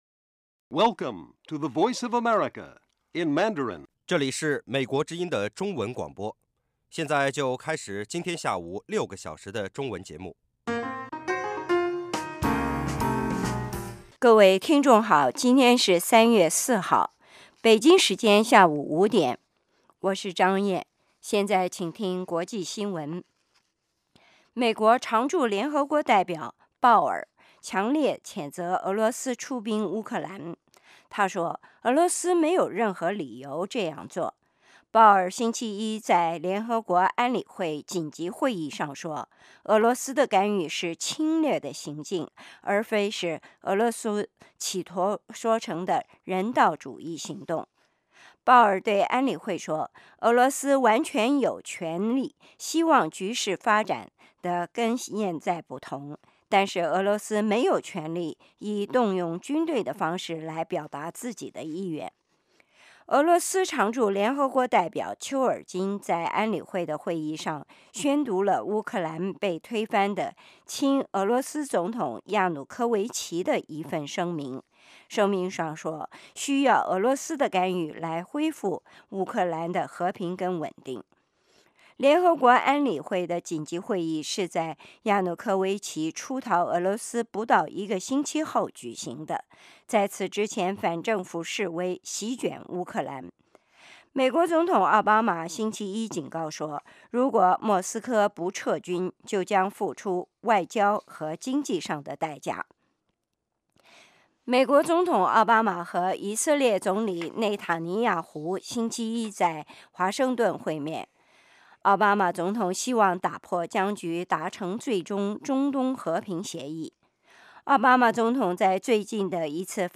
晚5-6点广播节目